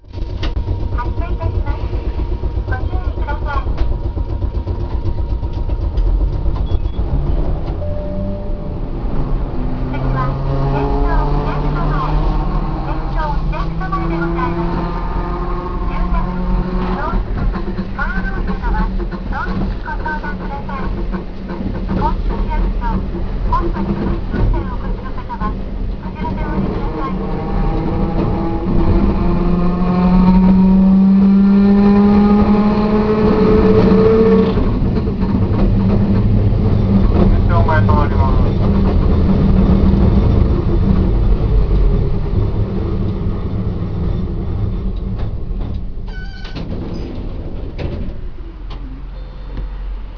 ・600形走行音
【伊野線】高知城前→県庁前（50秒：274KB）
当然ながら吊り掛け式です。